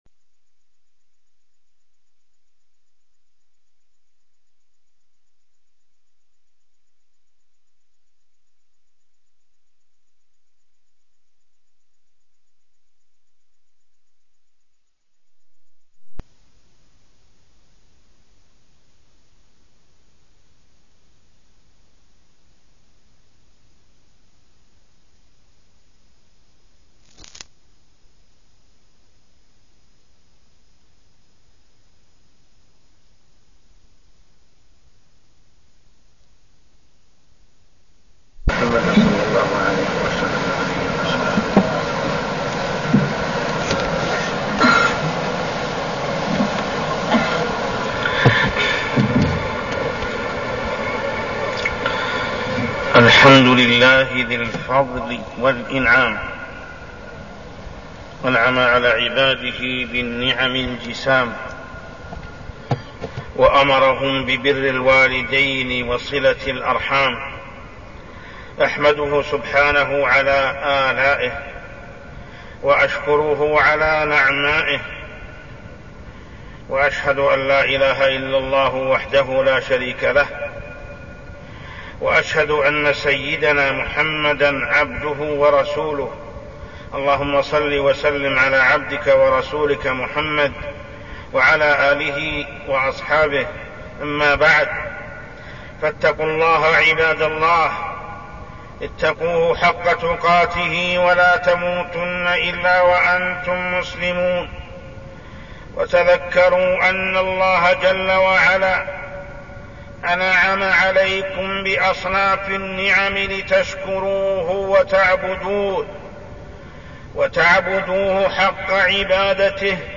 تاريخ النشر ٢٦ جمادى الأولى ١٤١٣ هـ المكان: المسجد الحرام الشيخ: محمد بن عبد الله السبيل محمد بن عبد الله السبيل حقوق الوالدين وبرهما